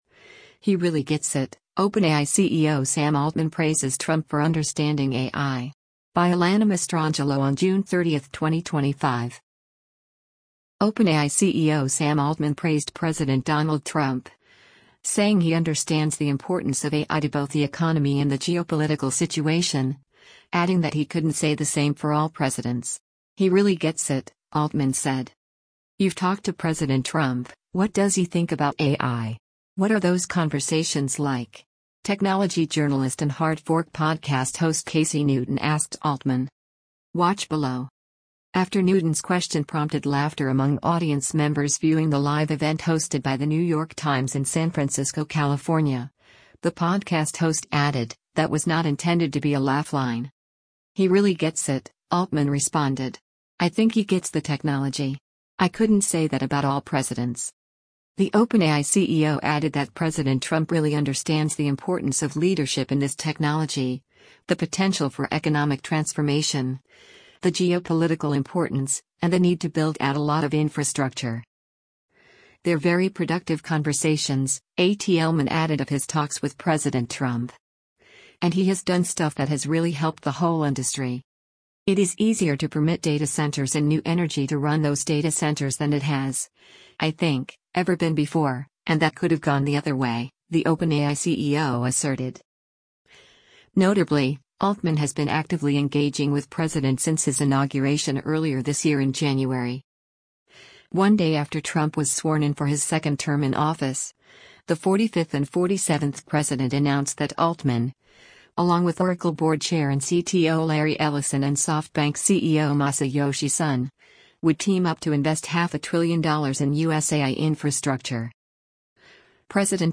“You’ve talked to President Trump, what does he think about AI? What are those conversations like?” technology journalist and Hard Fork podcast host Casey Newton asked Altman.
After Newton’s question prompted laughter among audience members viewing the live event hosted by the New York Times in San Francisco, California, the podcast host added, “That was not intended to be a laugh line.”